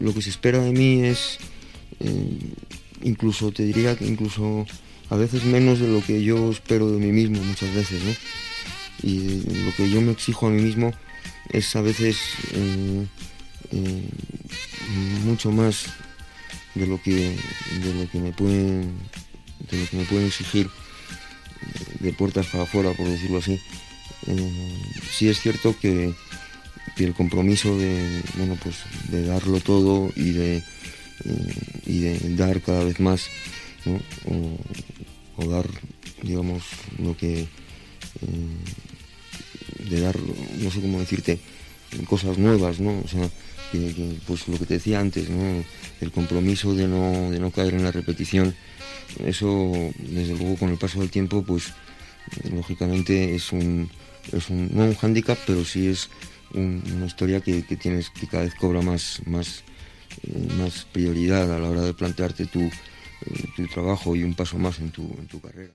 Resposta del cantant Anonio Vega en una entrevista